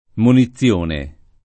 monizione [ moni ZZL1 ne ] s. f.